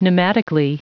Prononciation du mot pneumatically en anglais (fichier audio)
Prononciation du mot : pneumatically